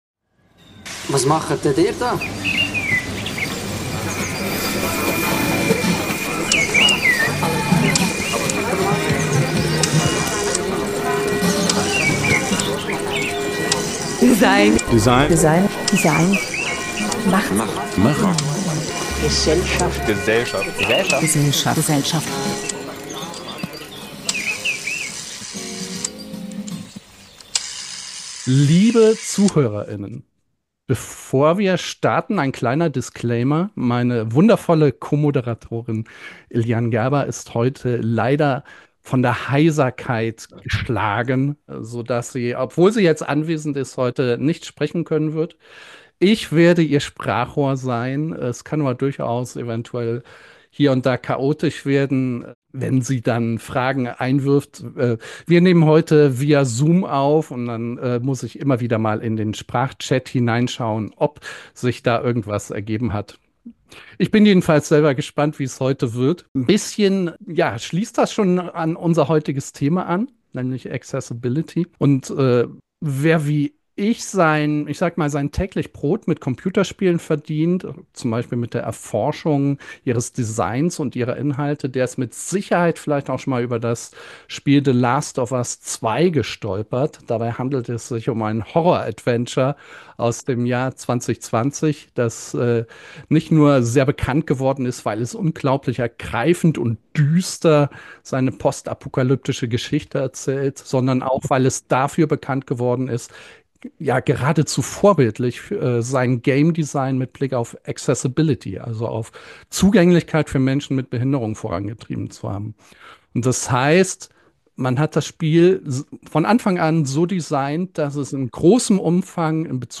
Eine Diskussion über die ethischen Implikationen von Universal Design und die Gestaltung einer inklusiven Gesellschaft.